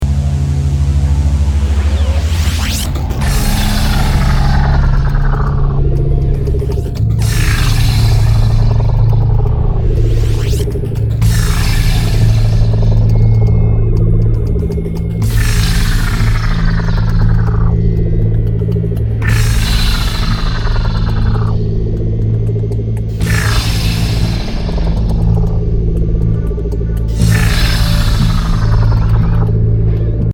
Sounds, rhythmic noise, and atmospheric constructions.
This is an instrumental disc with 1 spoken word track.